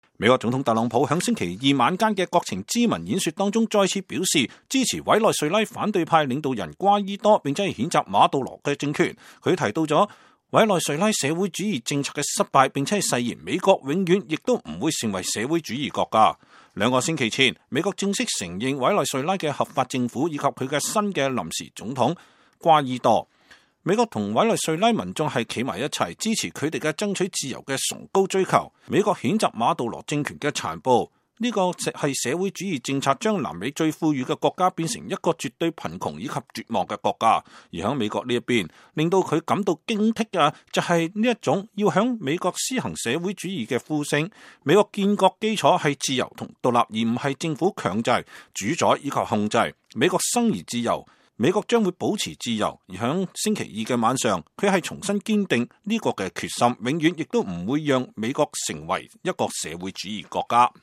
特朗普總統在國會發表國情咨文演說。 (2019年2月5日)